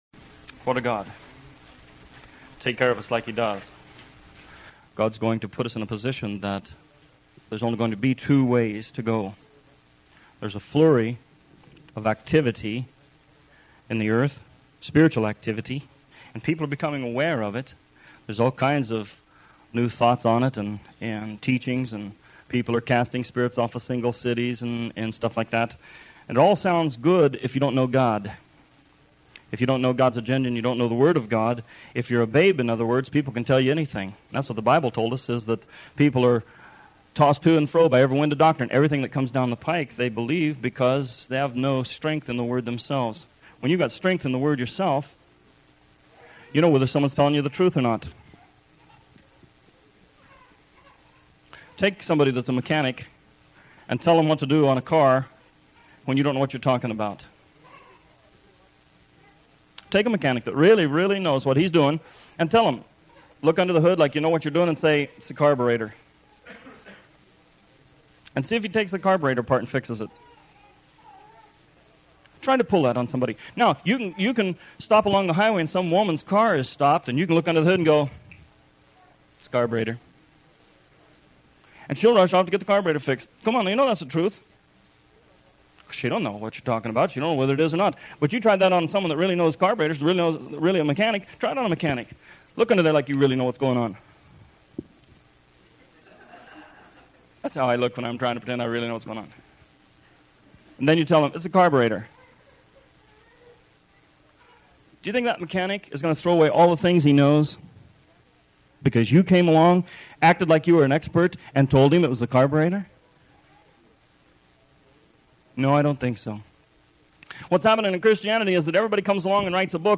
This sermon talks about coveting the best gifts and how God has given gifts to the church in order for the world to be touched on an individual basis.